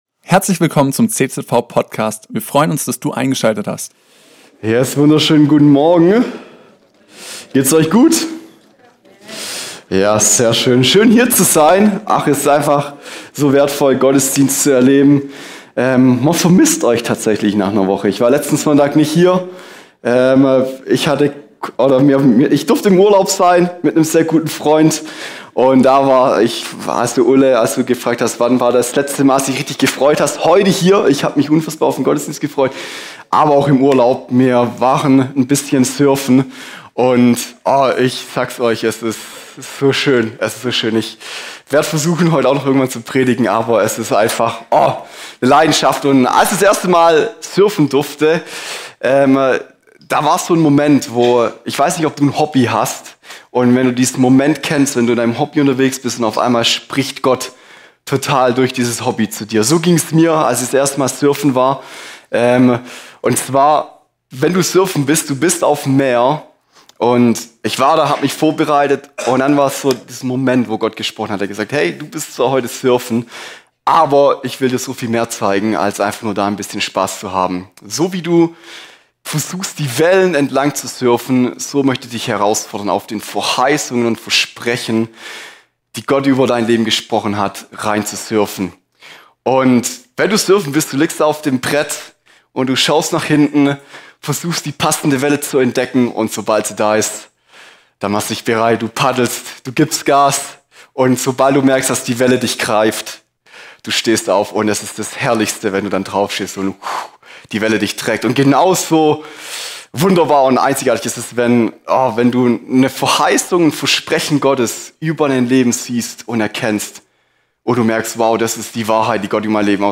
Auftakt unserer neuen Predigtserie über den Kolosserbrief.